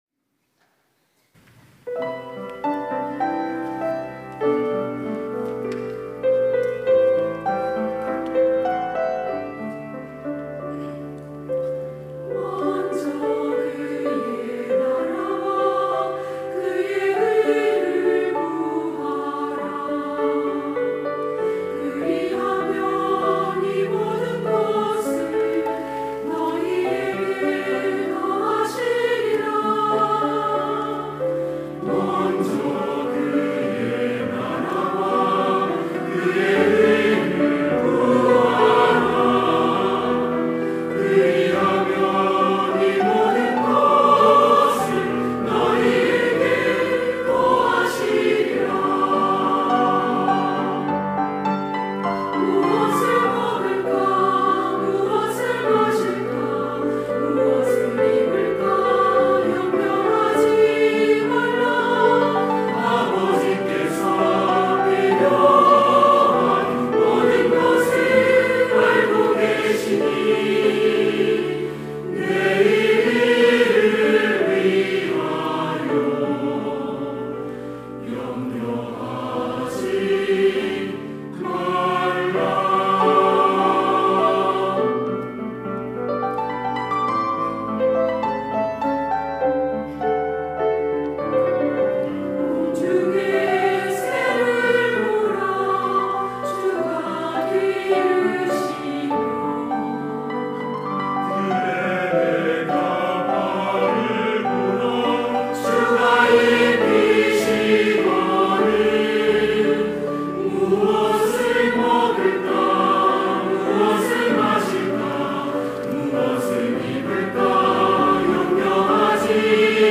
시온(주일1부) - 먼저 그의 나라와
찬양대